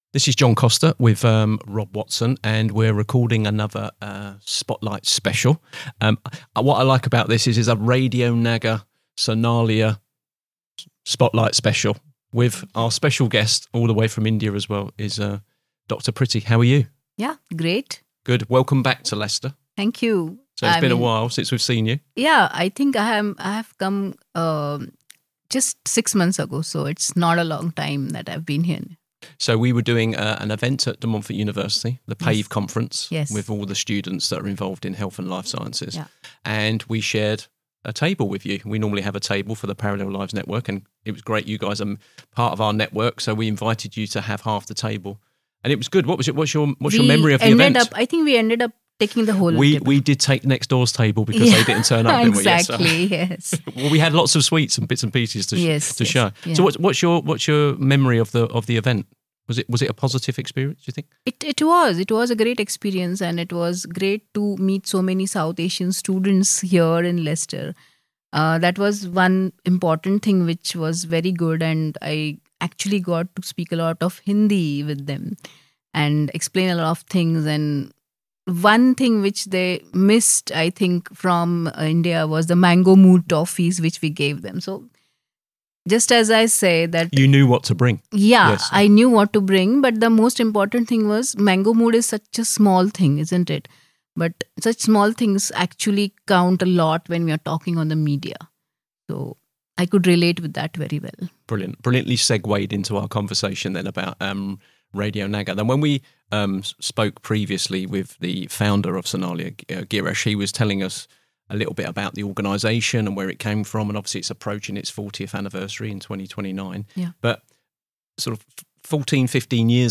The conversation offers an inspiring and reflective look at how Radio Nagar 90.4 FM has developed over the past 15 years—not as a conventional broadcaster, but as a platform for voice, connection, and transformation.